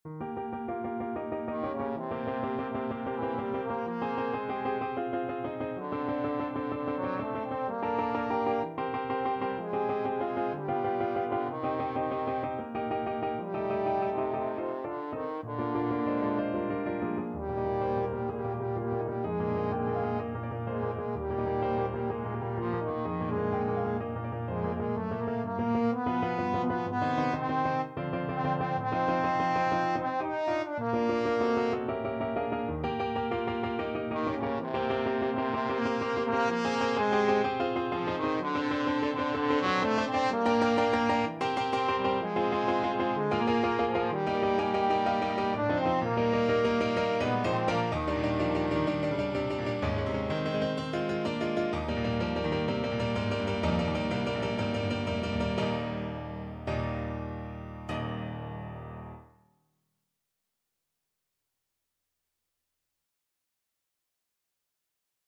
Classical
Trombone version
Tempo Marking: ~ = 63 Ziemlich rasch, leidenschaftlich Duration: 1:00 Range: C4-Eb5 Score Key: Eb major (Sounding Pitch) ( View more Eb major Music for Trombone ) Time Signature: 2/4 ( View more 2/4 Music ) Num